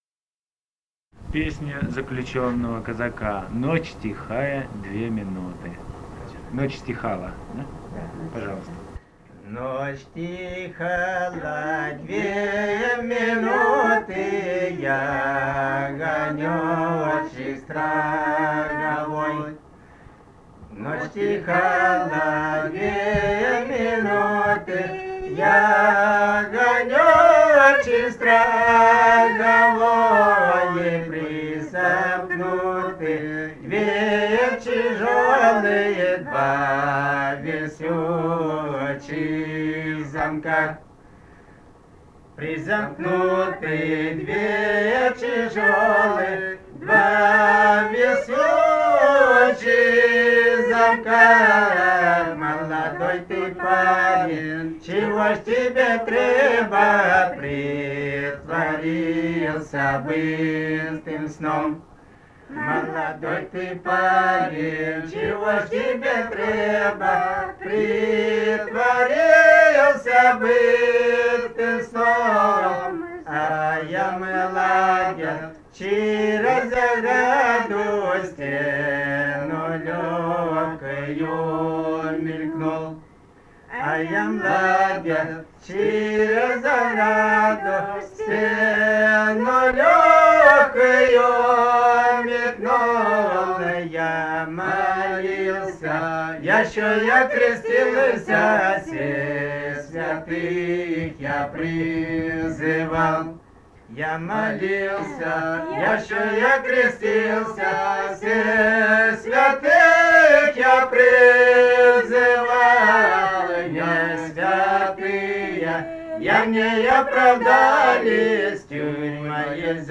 Свиридова И. К. Этномузыкологические исследования и полевые материалы 154. «Ночь стихала две минуты» (тюремная).
Ставропольский край, пос. Терек Прикумского (Будённовского) района, 1963 г. И0717-10